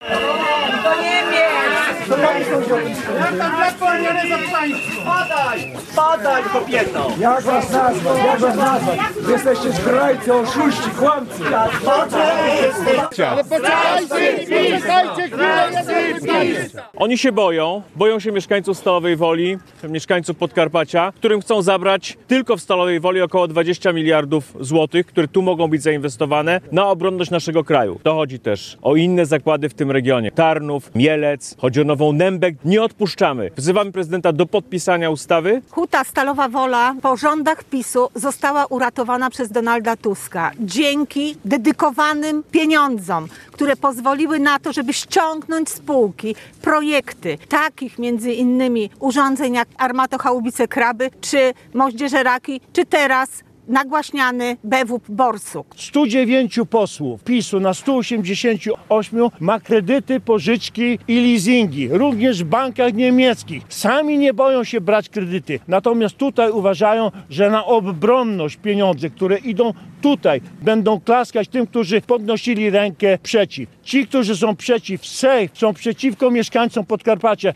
Wiadomości • Politycy podkarpackiej Koalicji Obywatelskiej ponownie zaapelowali dziś (21.02) w Stalowej Woli do prezydenta Karola Nawrockiego o podpisanie ustawy wdrażającej w Polsce unijny program na rzecz obronności SAFE.
Konferencja przed Muzeum Centralnego Okręgu Przemysłowego wzbudziła duże emocje.
Skandowano również hasło: „Zdrajcy PiS”.